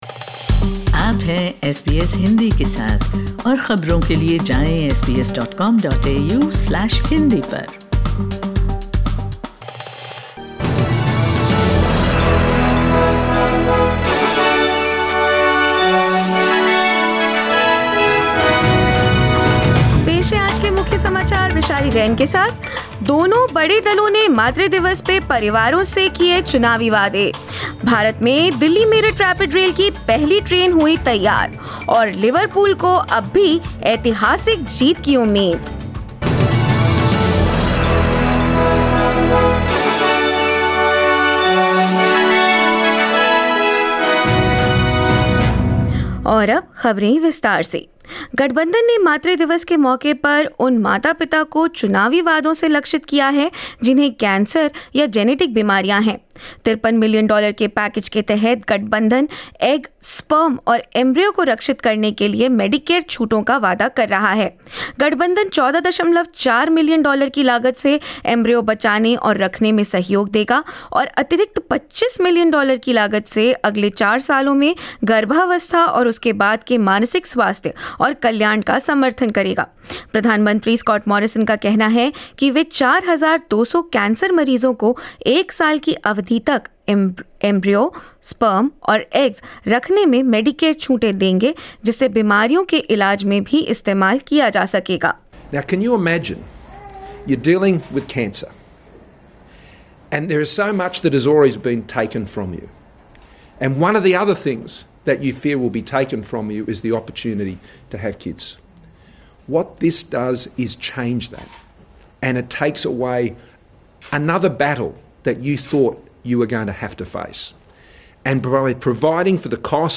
In this latest SBS Hindi bulletin: Both major parties capitalise on Mothers Day by focusing their election campaign toward families; In India, Alstom India hands over the first Rapid Rail to the authorities; Liverpool manager Jürgen Klopp says his team will still chase an unprecedented quadruple and more news.